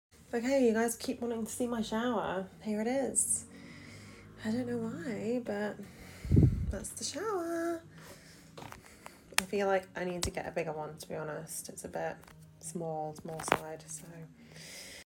Glass shower panels or reflection sound effects free download